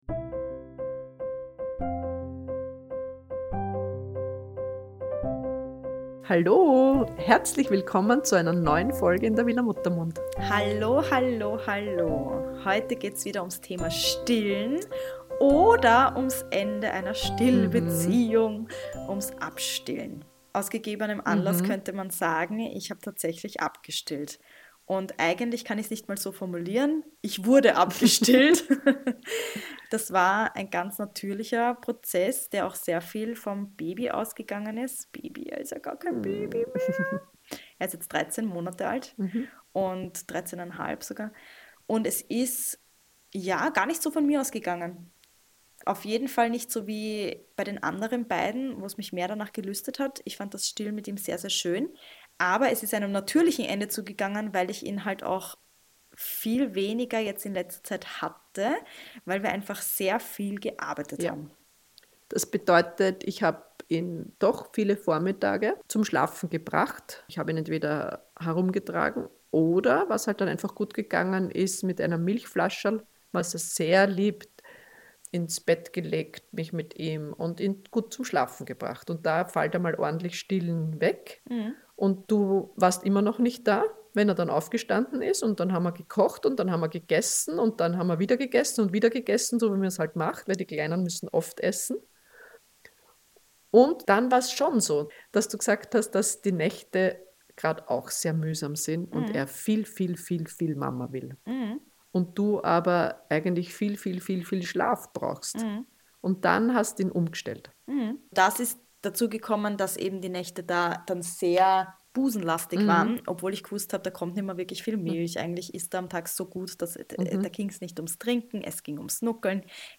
Eine Plauderei über das Abstillen